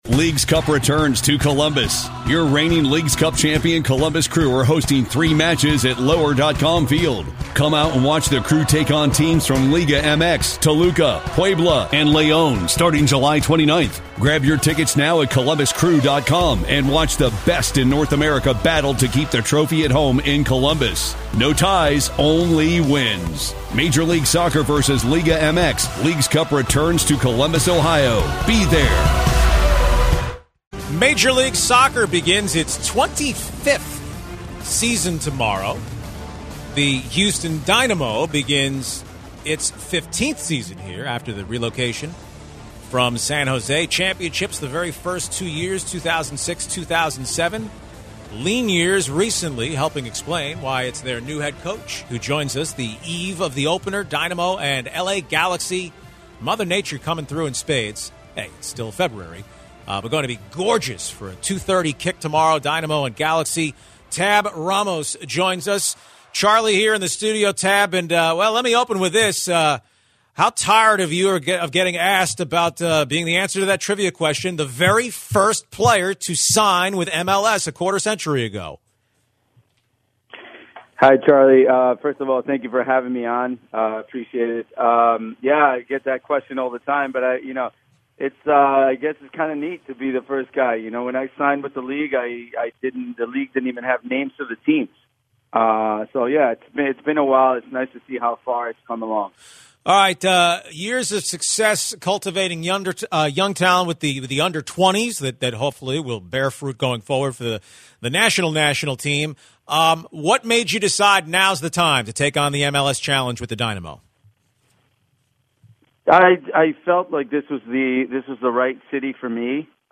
Houston Dynamo head coach Tab Ramos joins the show ahead of his first season at the helm of the team.